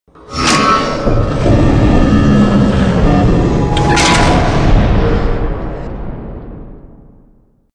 File:Sfx animation Dreadeon win.ogg